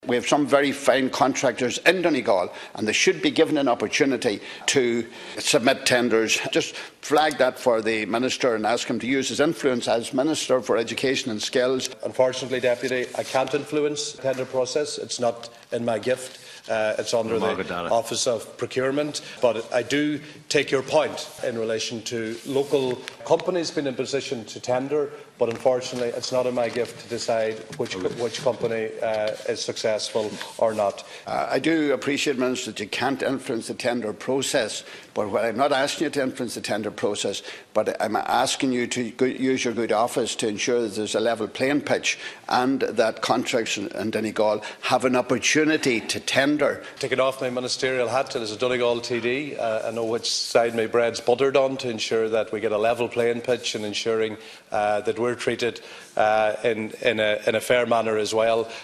The issue was raised during a discussion in the Dail this week by Leass Cheann Comhairle and Donegal Deputy Pat the Cope Gallagher.
However, Minister Mc Hugh stressed that while he agrees with the sentiment, he can’t influence the process: